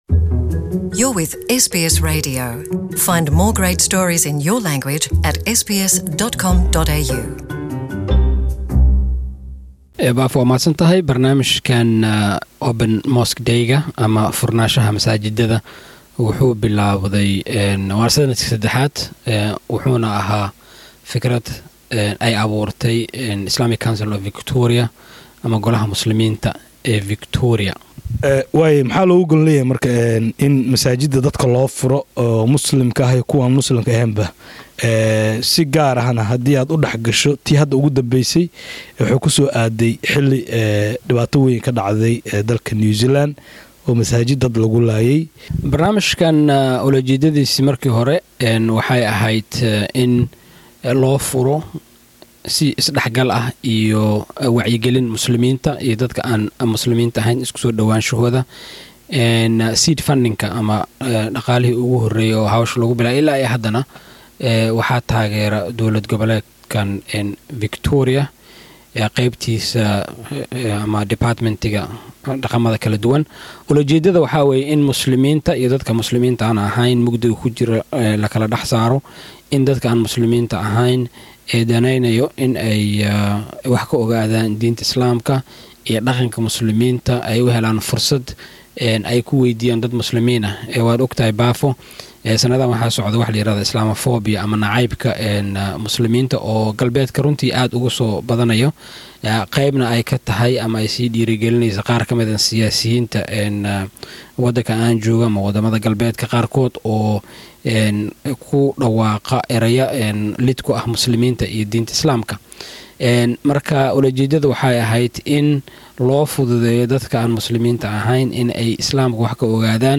Mosque open day interview
Waraysi: Maalinta Masjidka dadka loo furay.